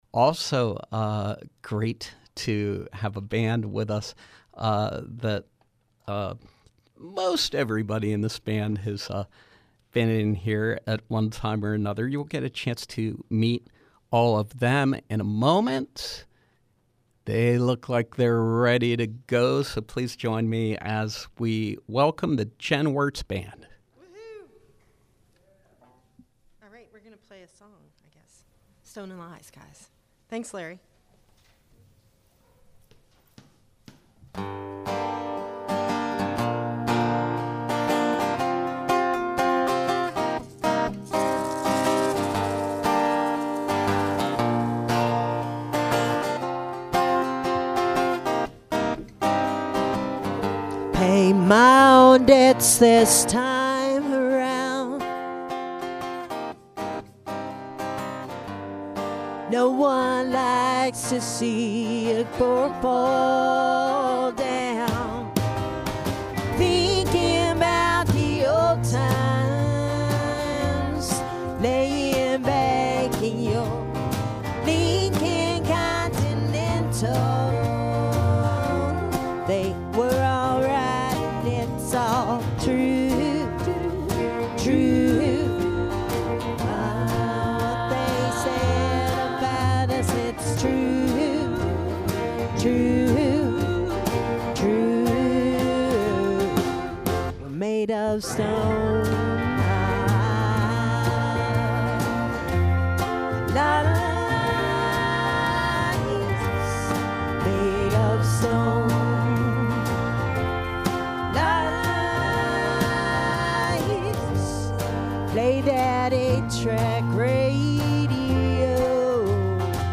Live music